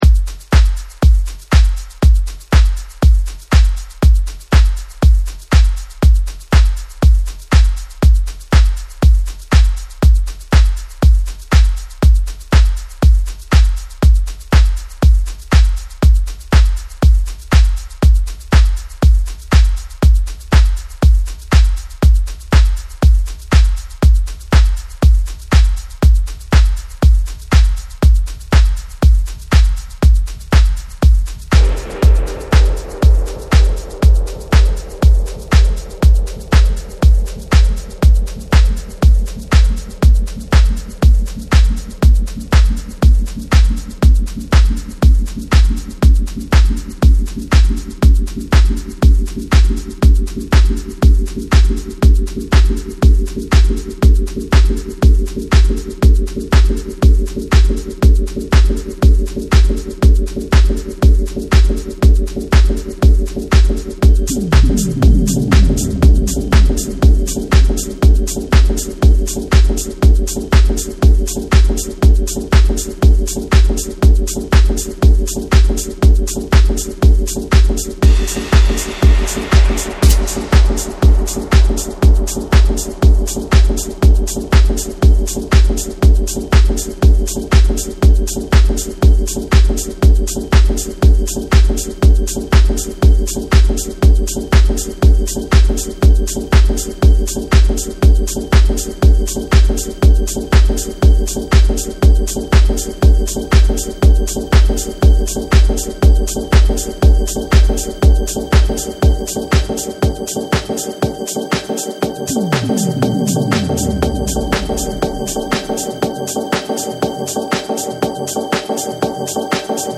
淡々と刻むシンプルなビートがジワジワと来る感覚。ジラしてタメて一気に放出。エフェクトの使い方も抜群だね。